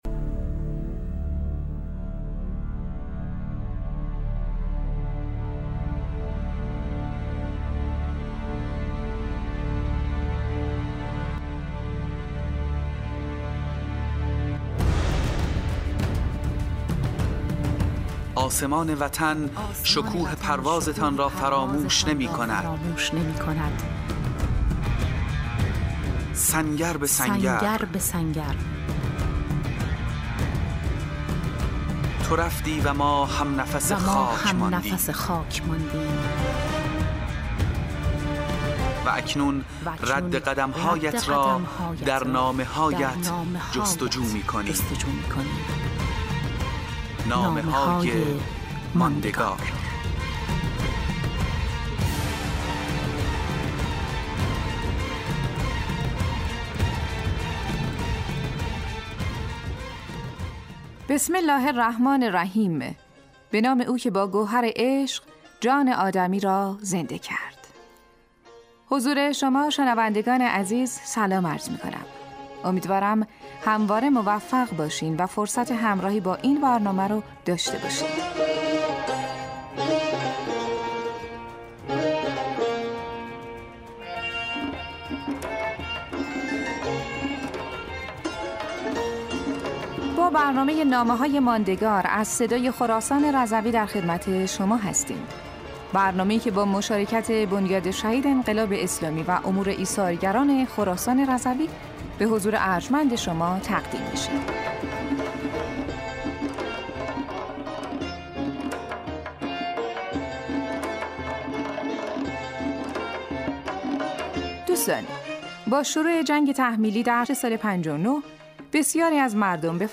خوانش نامه‌های شهدای خراسان رضوی در برنامه رادیویی نامه‌های ماندگار / قسمت اول